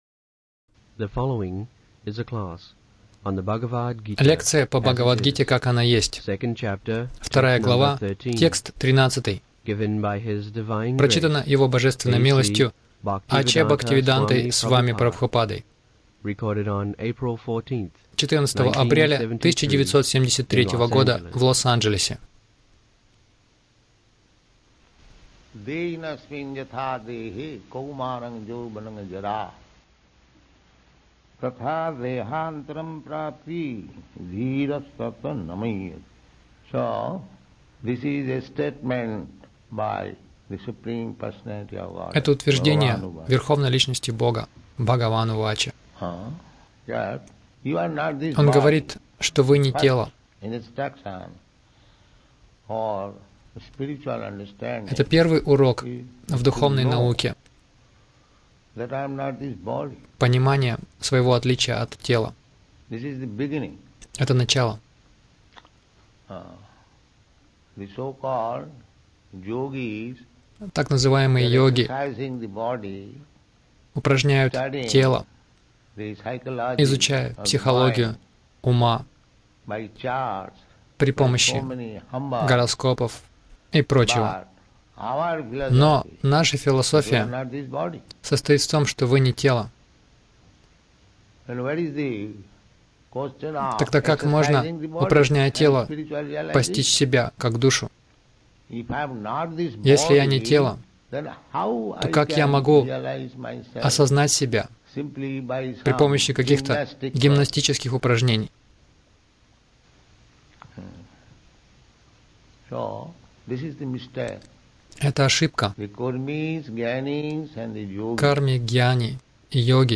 Милость Прабхупады Аудиолекции и книги 14.04.1973 Бхагавад Гита | Лос-Анджелес БГ 02.13 — Первый урок Бхагавад Гиты Загрузка...